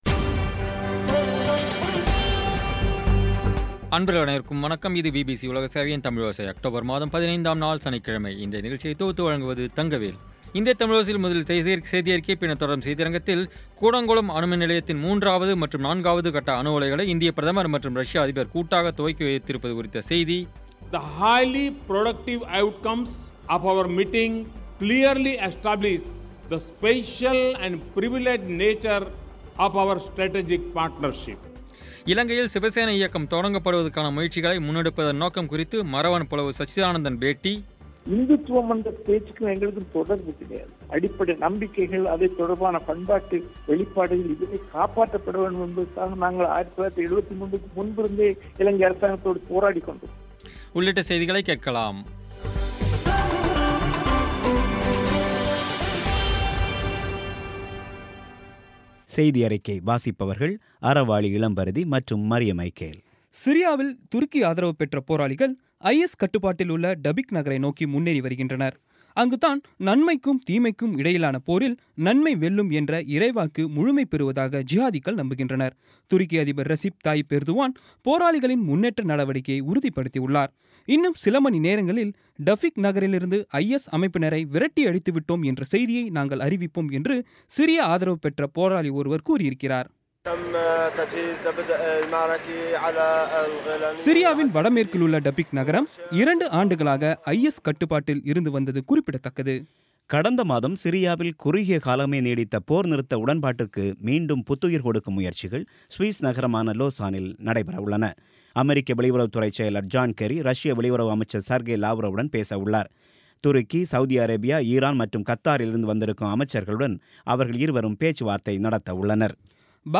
பேட்டி